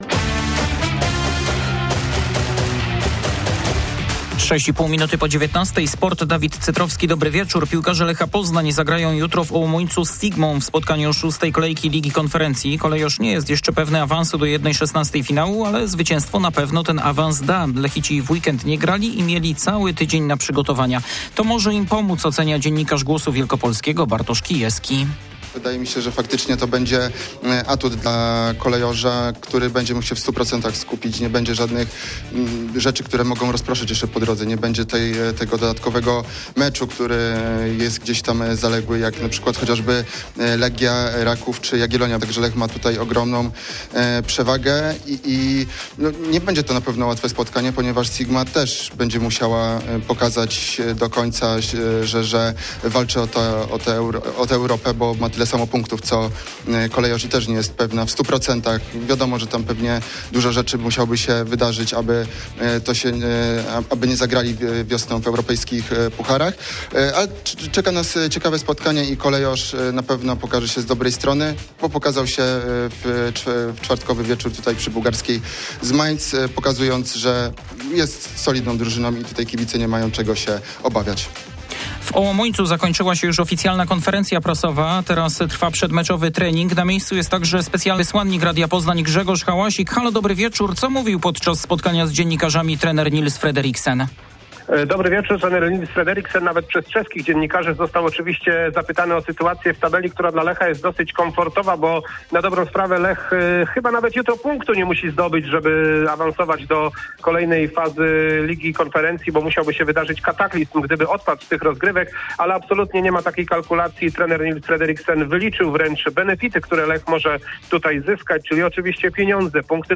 17.12.2025 SERWIS SPORTOWY GODZ. 19:05
W środowym serwisie łączenie live z Ołomuńcem, gdzie trwa konferencja prasowa Lecha Poznan przed meczem z Sigmą. Ponadto o formie koszykarek AZSu przed spotkaniem z outsiderem z Jeleniej Góry.